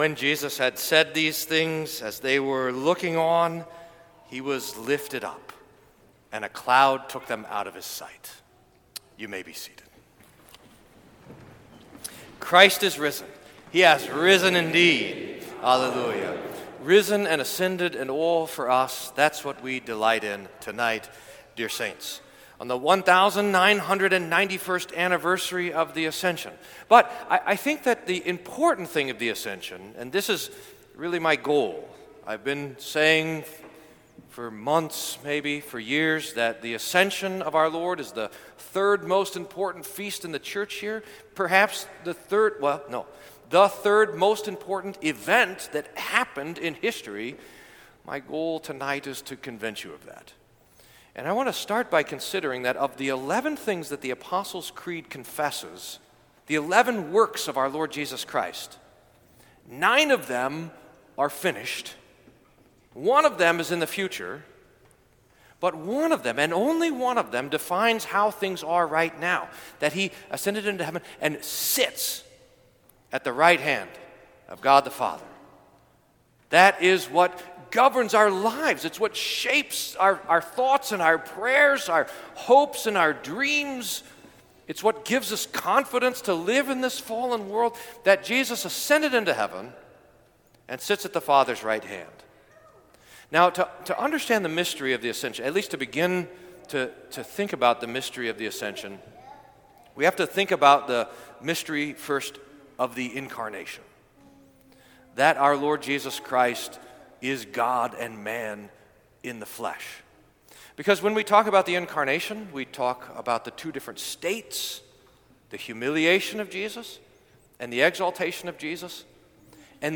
Sermon for the Ascension of Our Lord